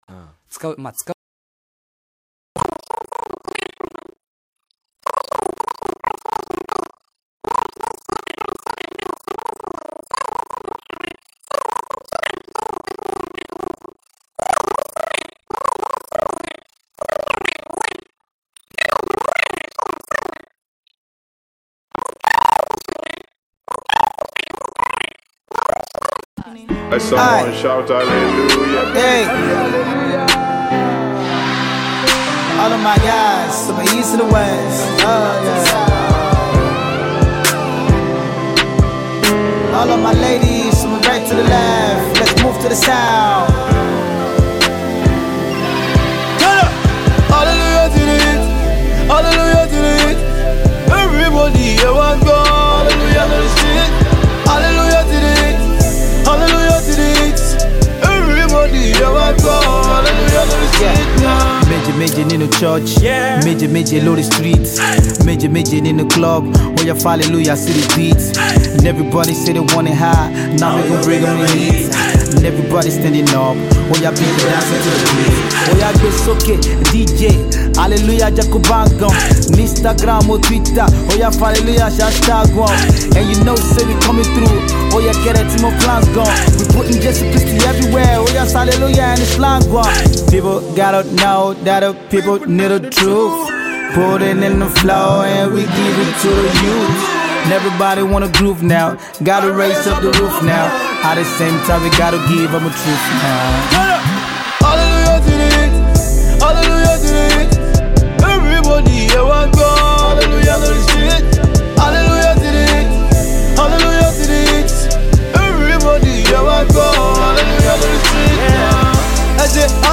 Nigerian Singer/Rapper